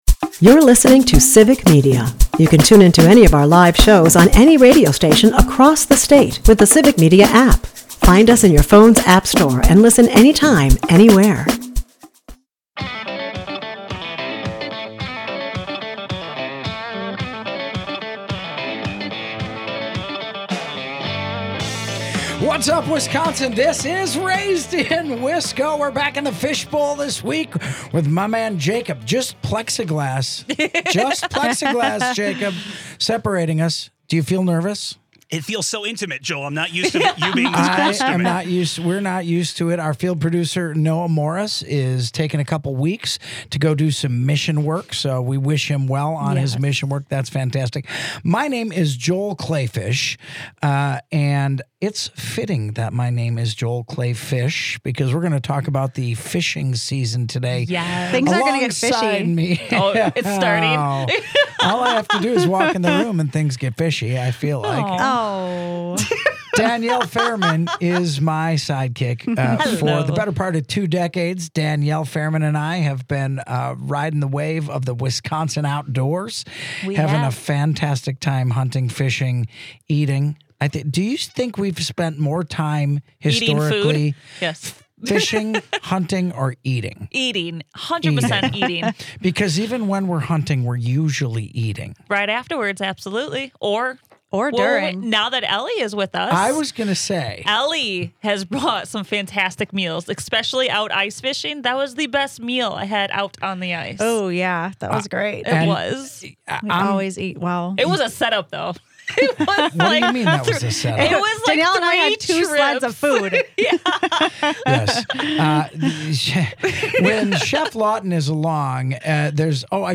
raised in wisco Leaves Mushroom for Conversation 6/14/2025 Listen Share In the first of three special shows live from the Fish Bowl